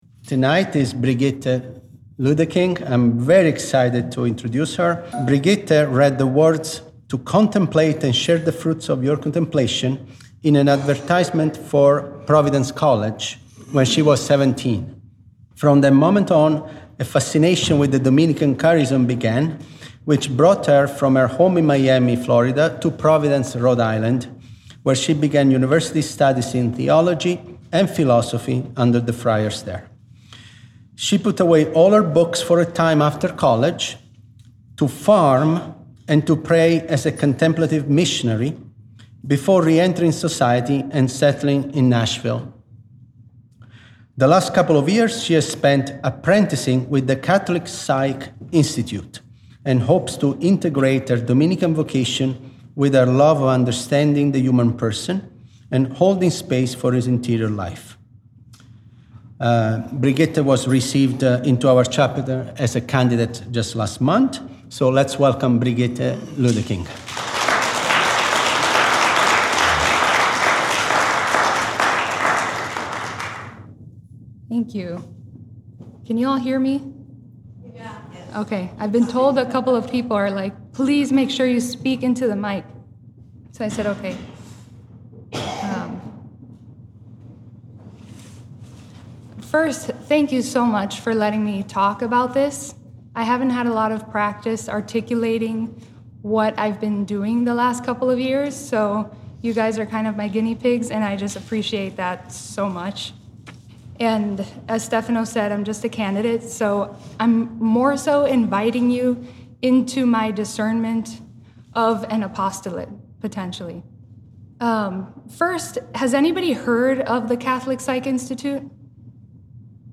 Chapter Meeting